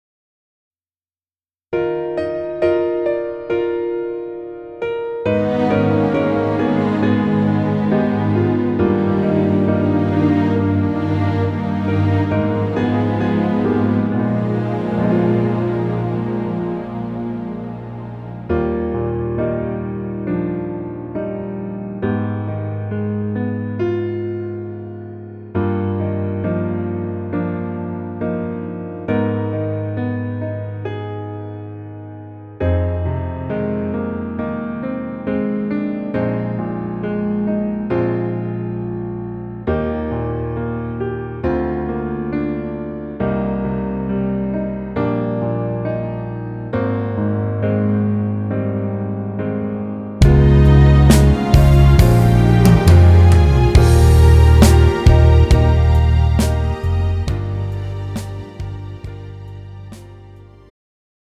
음정 -2키
장르 축가 구분 Pro MR
가사   (1절 앞소절 -중간삭제- 2절 후렴연결 편집)